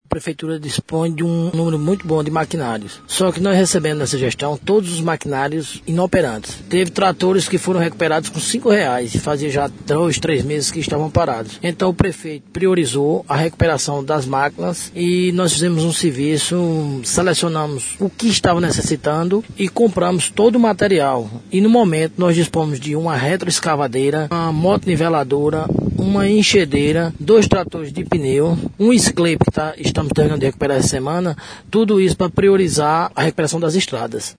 Secretário adjunto de Agricultura, Luiz Claudio –